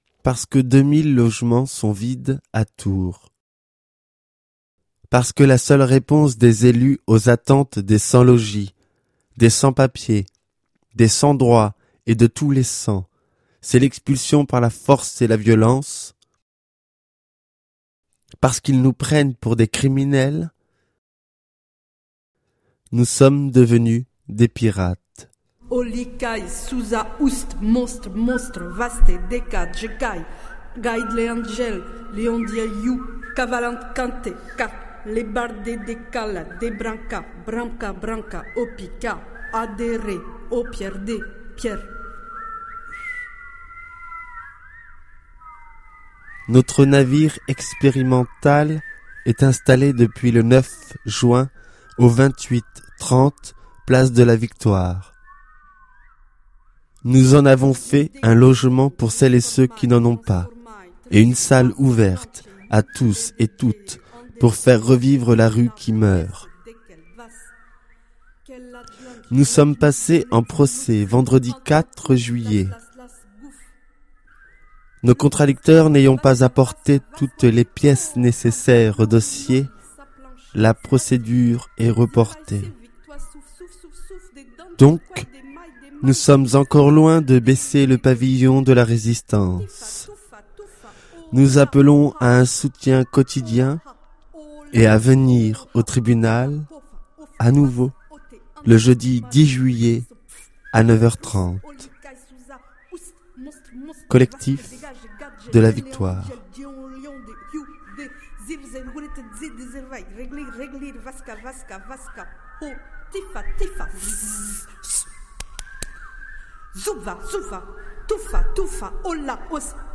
Troisi�me partie du documentaire